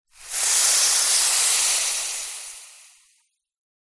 Звуки химии
Кислотный ожог и шипение часть 1